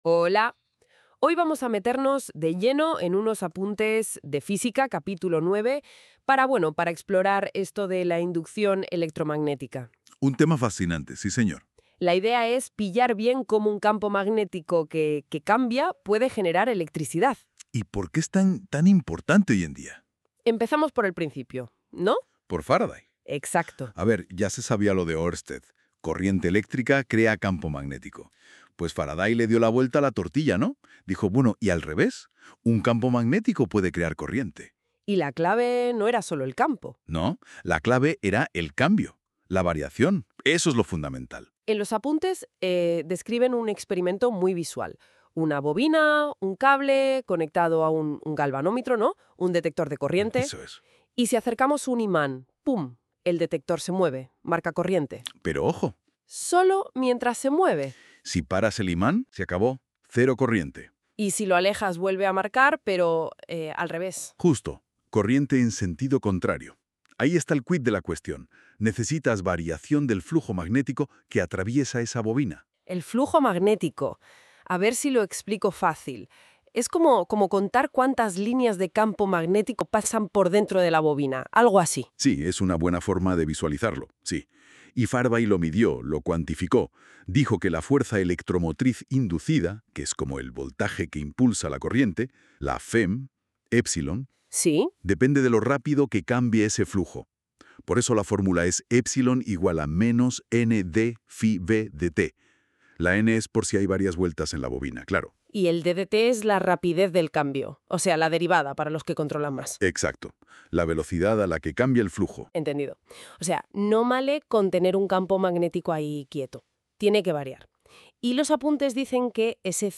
El presente material ha sido generado mediante NotebookLM, una herramienta de inteligencia artificial desarrollada por Google.